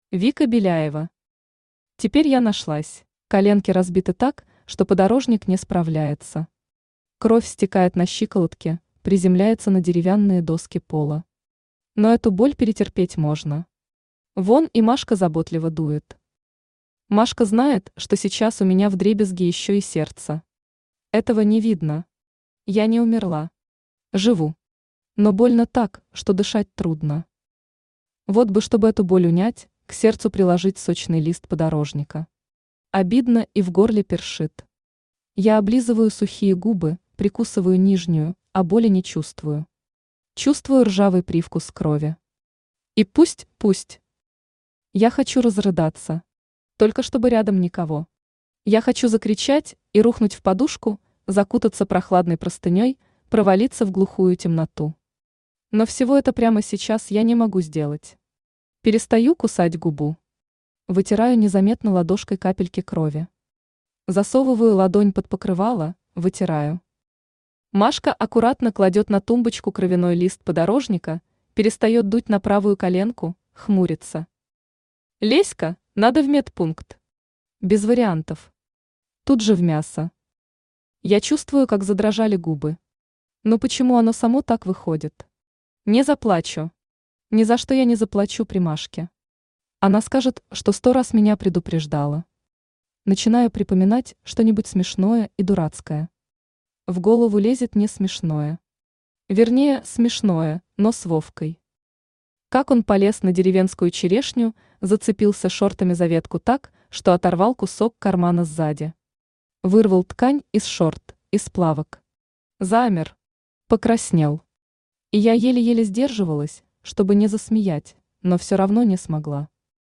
Аудиокнига Теперь я нашлась | Библиотека аудиокниг
Aудиокнига Теперь я нашлась Автор Вика Беляева Читает аудиокнигу Авточтец ЛитРес.